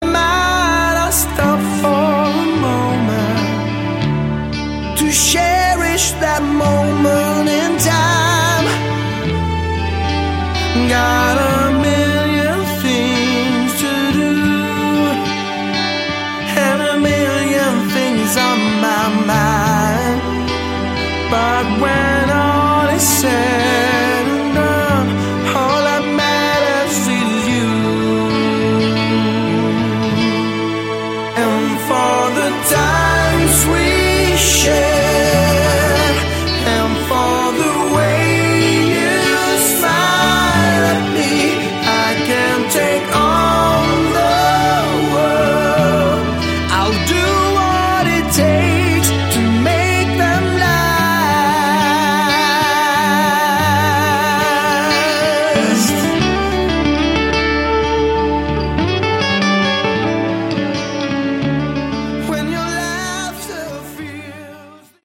Category: AOR
Drums
Keyboards, Backing Vocals
Guitars, Backing Vocals
Lead Vocals
Bass, Backing Vocals
Very Melodic.